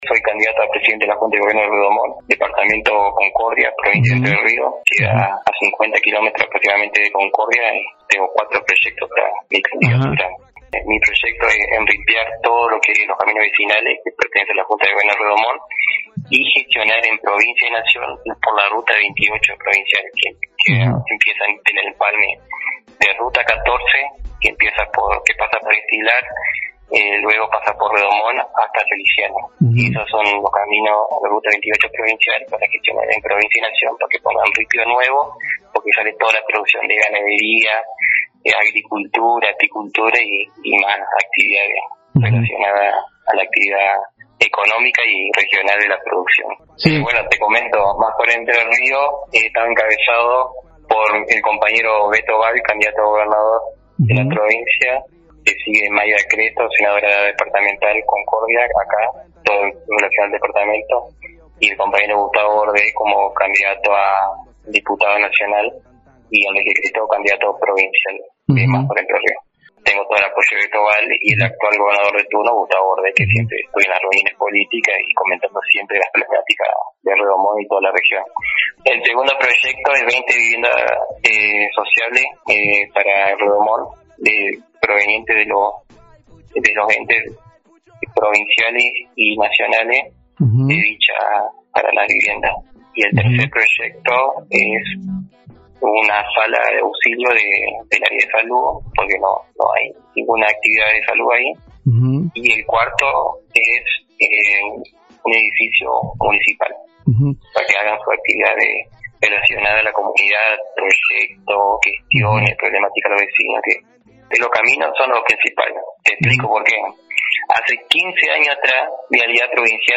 en una entrevista exclusiva con Corredor del litoral